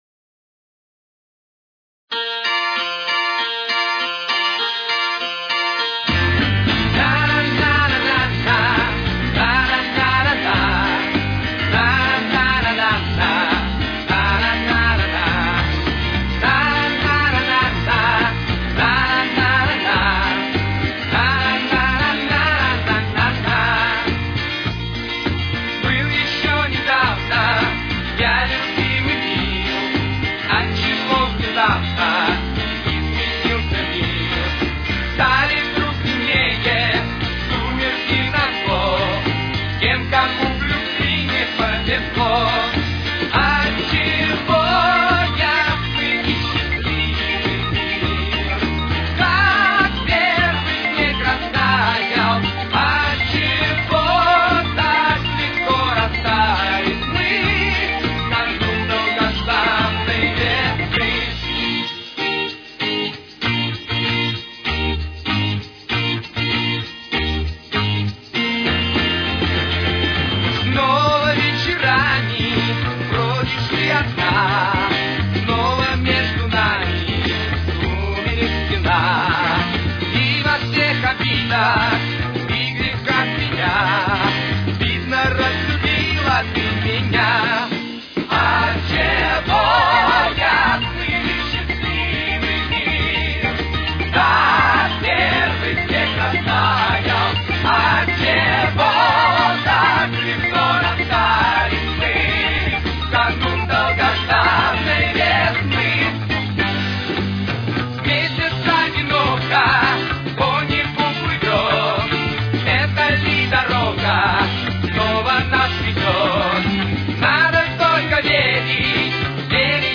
с очень низким качеством (16 – 32 кБит/с)
Тональность: Си-бемоль мажор. Темп: 105.